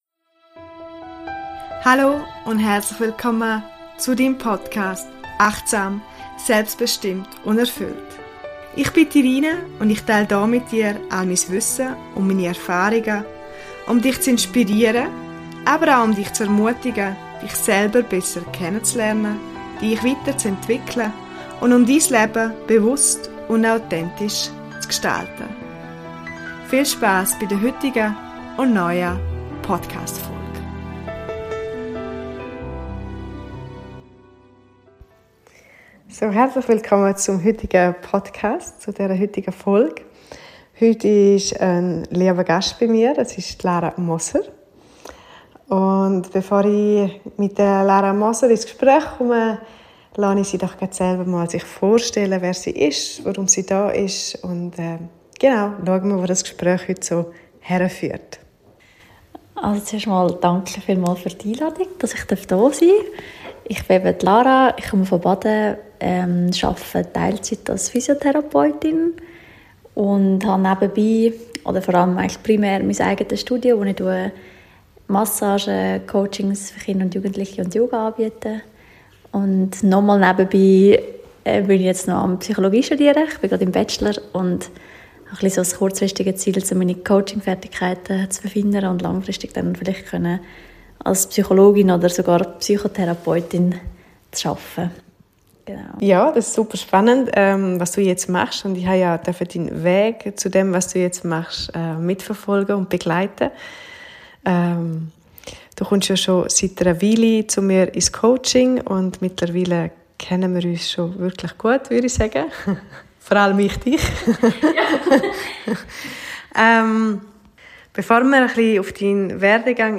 Ganzheitliches Coaching im Fokus - Interview